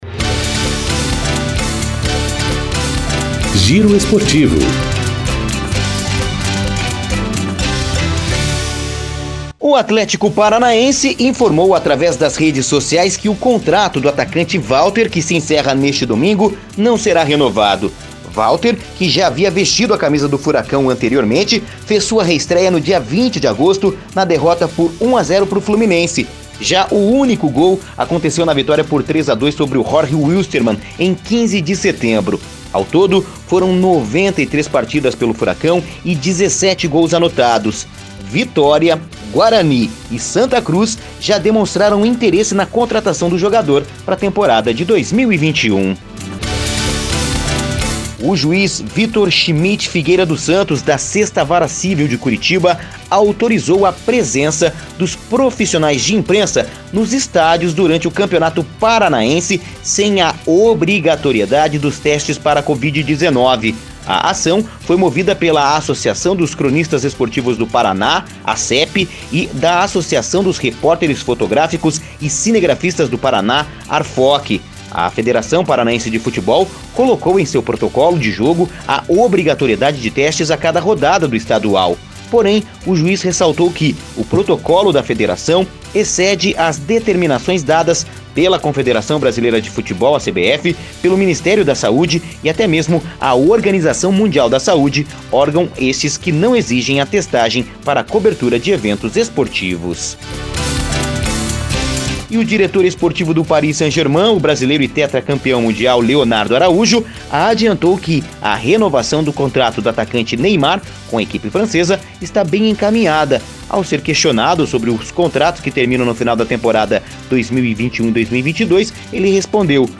Giro Esportivo (COM TRILHA)